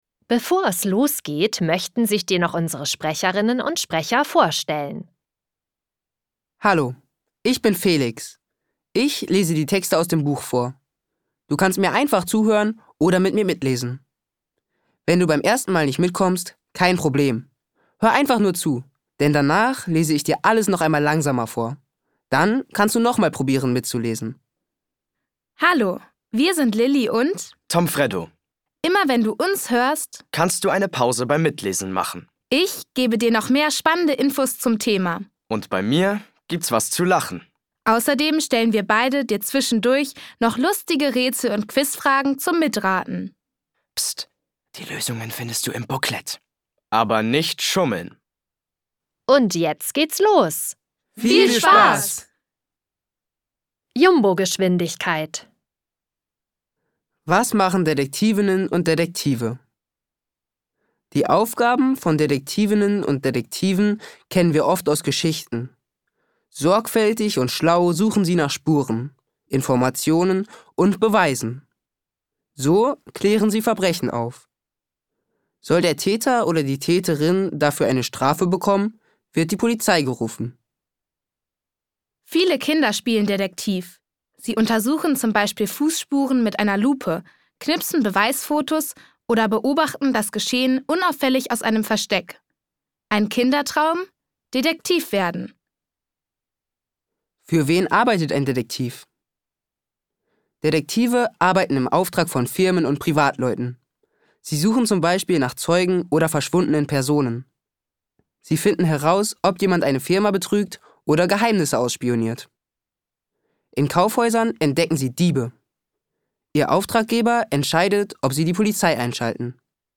Hörbuch: Wieso?